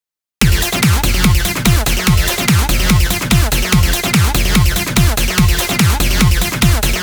【EQ処理後】
こちらはローカット以外にも複数トラックにEQ処理をした結果となりますので、ローカット以外についてはおって説明します。
ひとまずここで着目してもらいたい点はアシッド音(なんかウニョウニョなってる電子音)です。